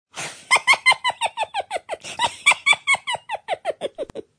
giggle.mp3